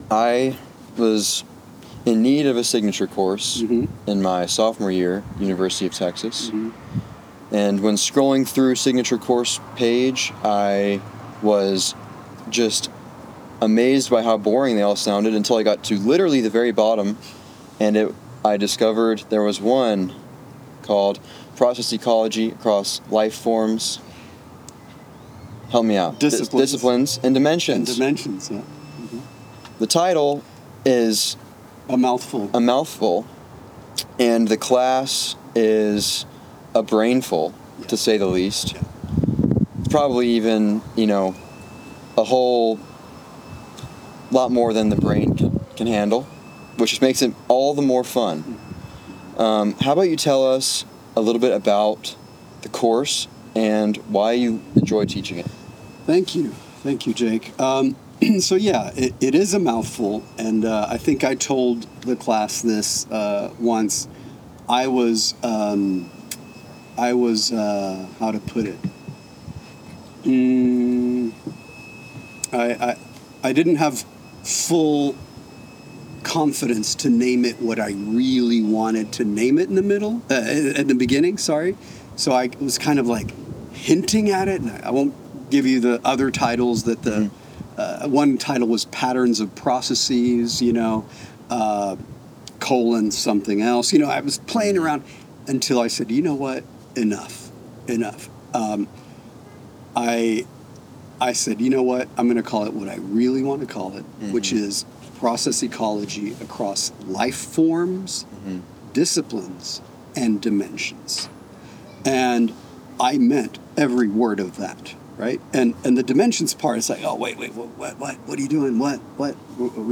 Interview about Process Ecology Course